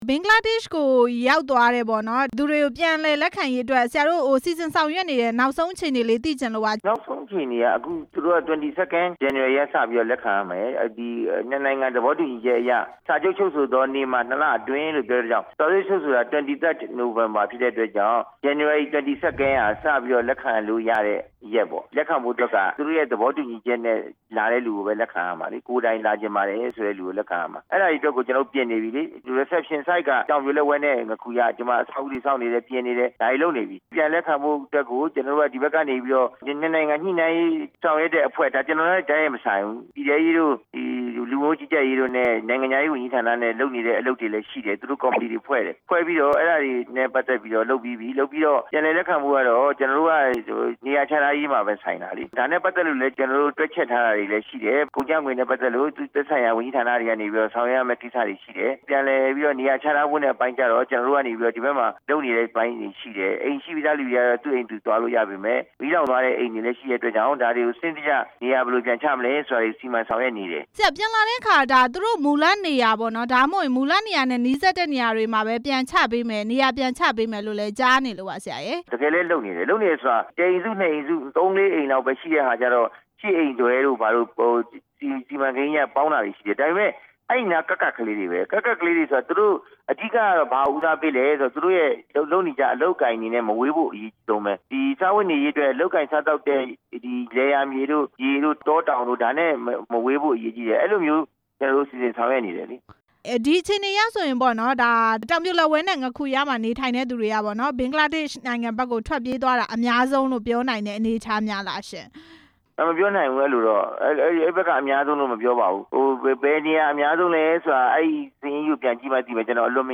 ဘင်္ဂလားဒေ့ရှ်ကနေ ပြန်လာမယ့်သူတွေကို လက်ခံမယ့်အကြောင်း မေးမြန်းချက်